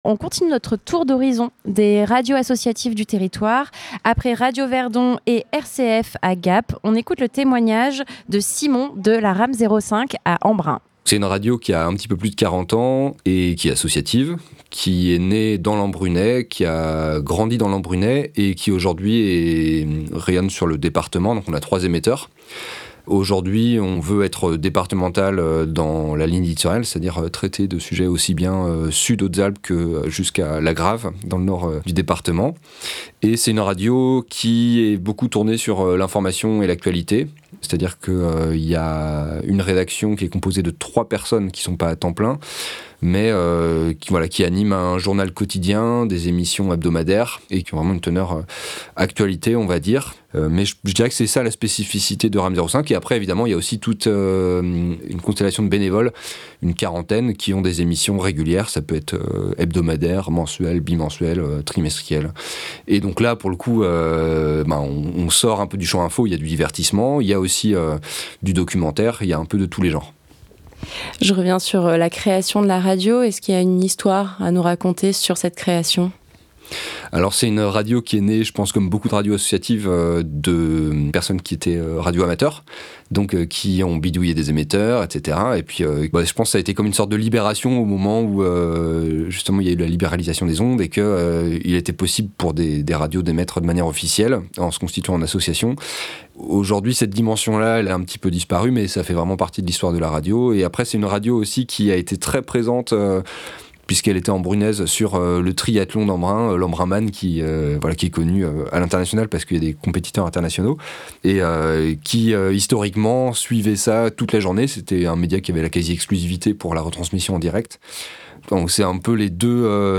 A cette occasion, toute l'équipe de Fréquence Mistral s'est retrouvée afin de vous proposer un plateau délocalisé en direct sur toute la journée sur Manosque. Retrouvez ci-dessous la rediffusion de l'interview de la RAM05 : Fete de la Radio 2025 - ITW RAM05.mp3 (39.06 Mo)